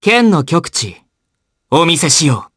voices / heroes / jp
Fluss-Vox_Skill7_jp.wav